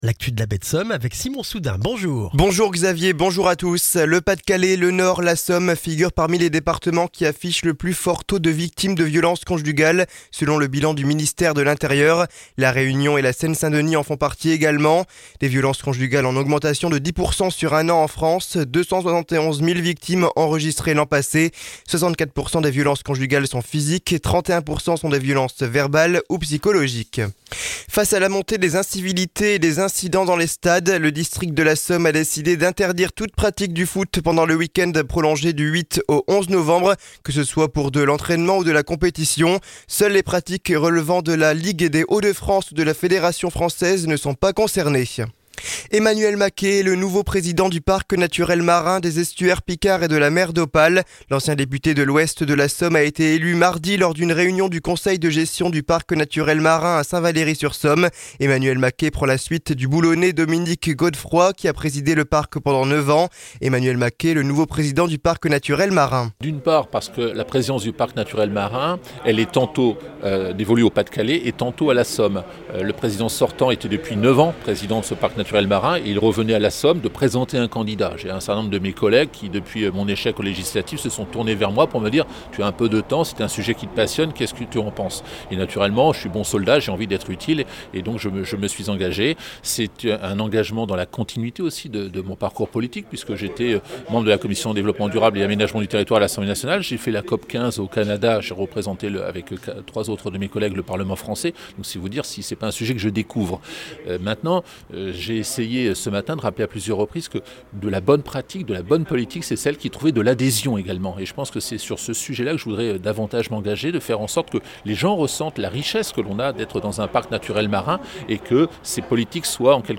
Le journal du jeudi 7 novembre en Baie de Somme et dans la région d'Abbeville